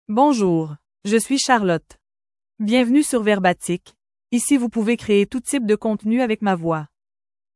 FemaleFrench (Canada)
CharlotteFemale French AI voice
Charlotte is a female AI voice for French (Canada).
Voice sample
Female